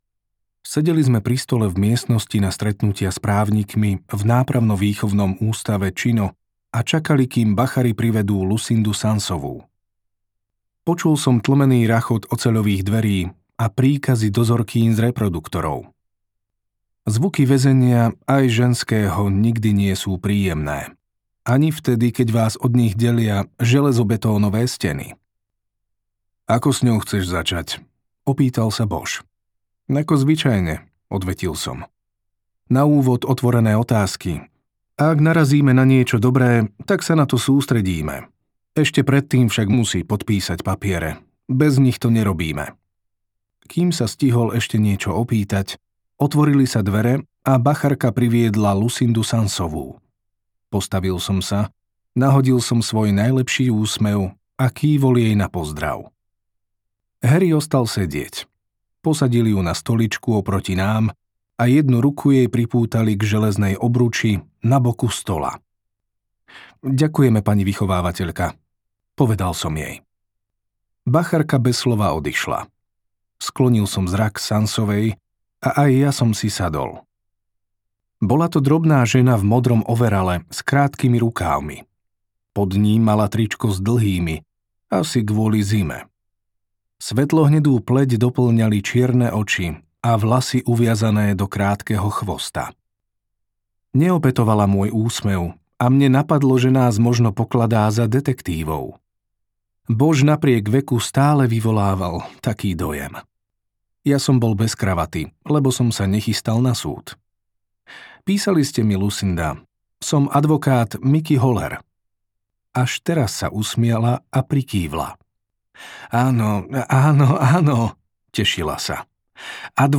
Cesta vzkriesenia audiokniha
Ukázka z knihy